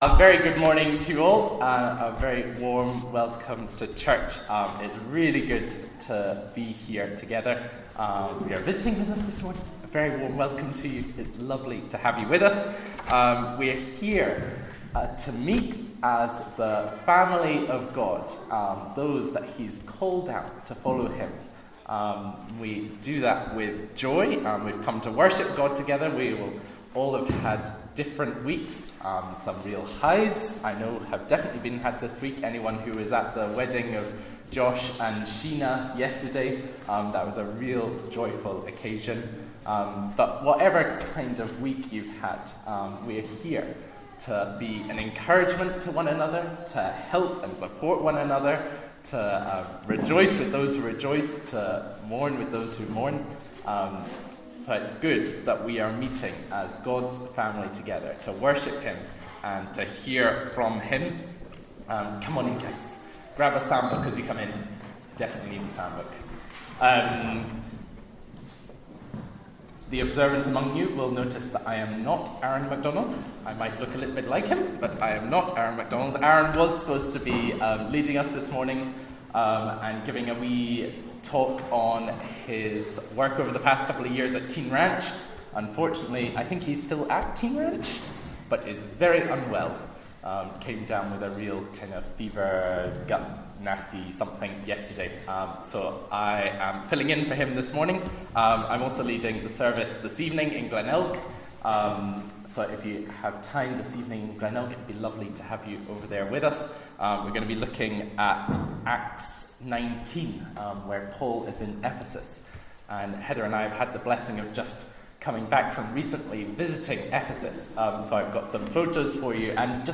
12-Noon-Service-1-3.mp3